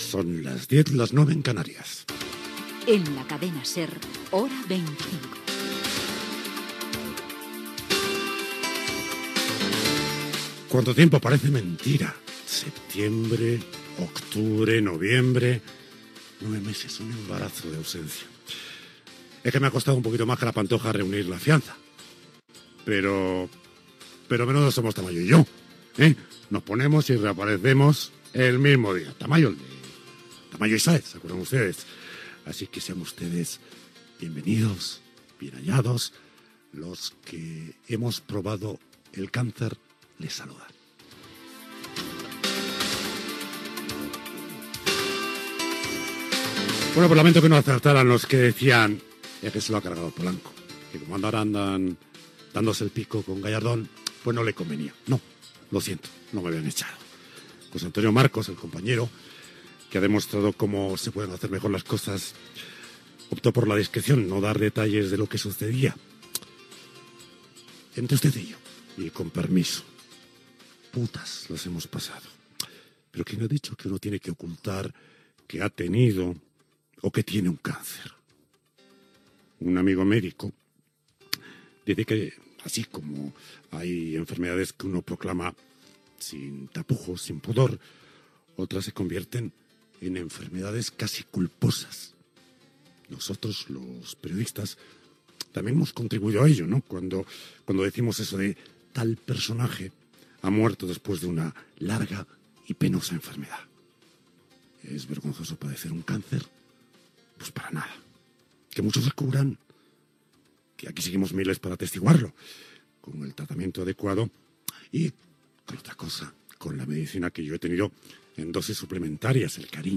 Hora, careta del programa, presentació del programa després d'estar Carlos Llamas fora dels micròfons 9 mesos degut a un càncer.
Informatiu